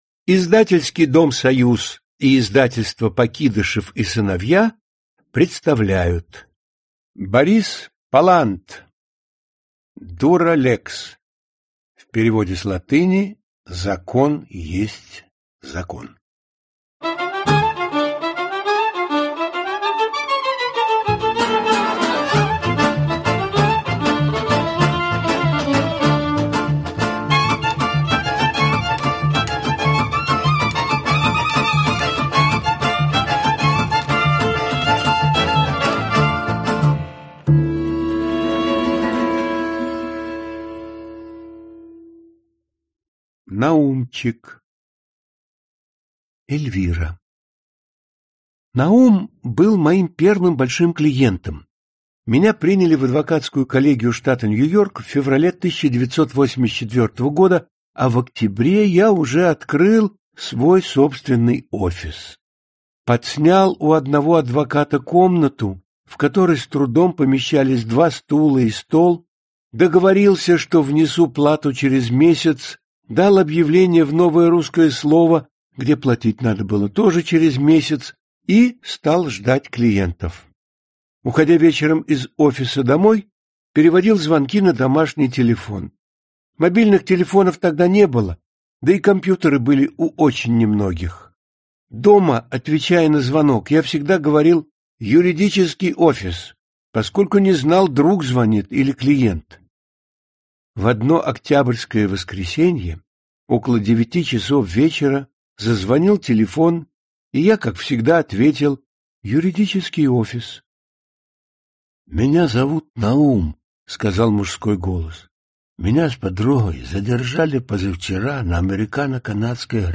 Часть 1 Автор Борис Палант Читает аудиокнигу Вениамин Смехов.